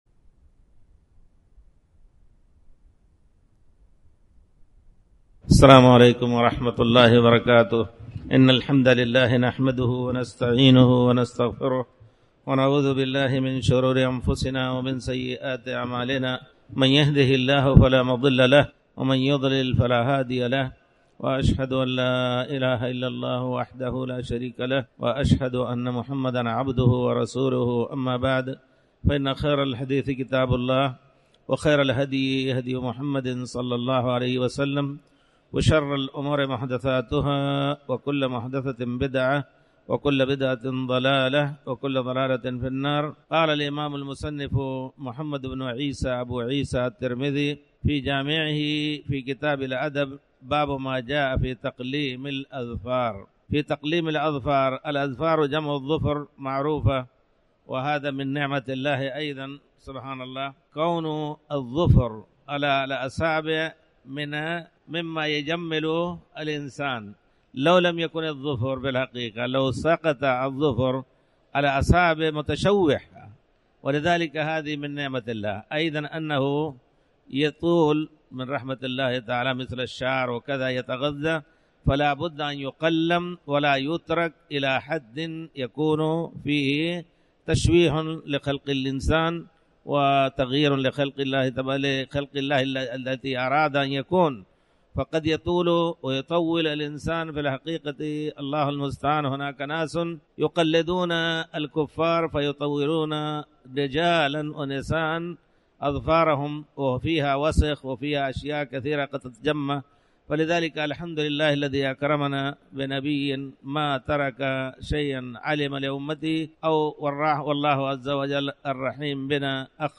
تاريخ النشر ١ رمضان ١٤٣٩ هـ المكان: المسجد الحرام الشيخ